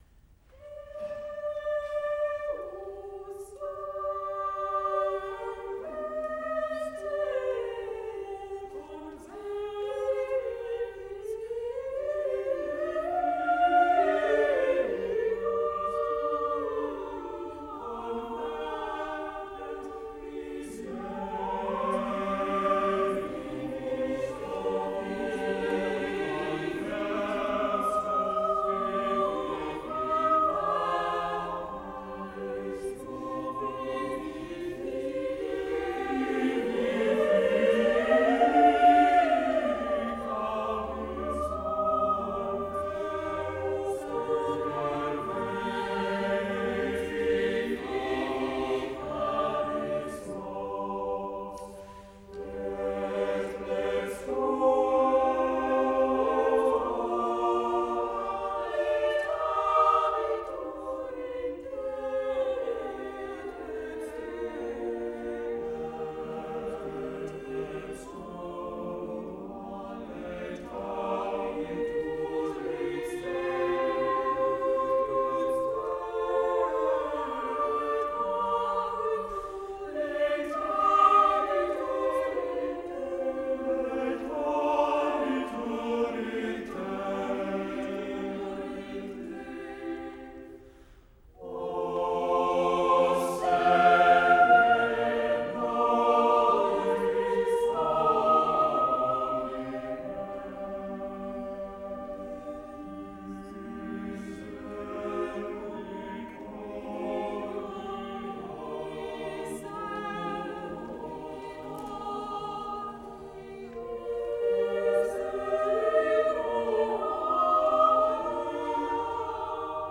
accomplished chamber choir